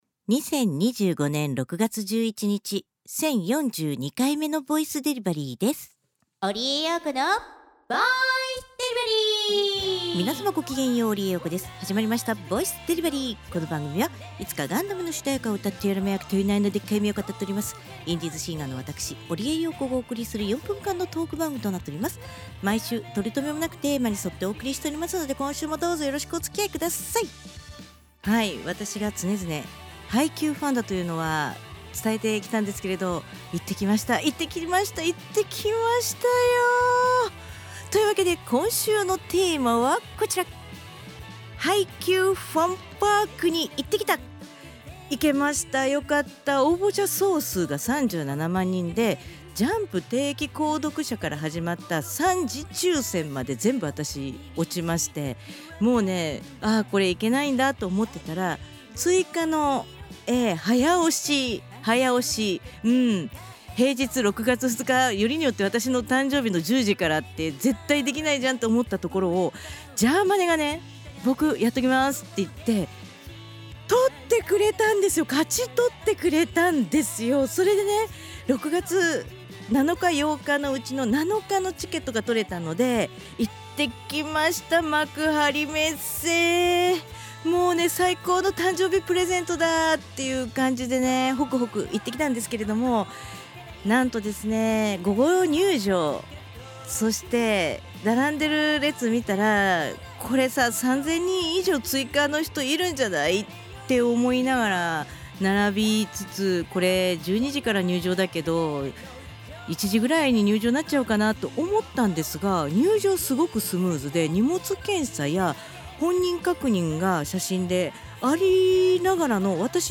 毎週水曜日か木曜日更新の４分間のトーク番組（通称：ぼいでり）時々日記とTwitterアーカイブ